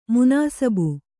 ♪ munāsabu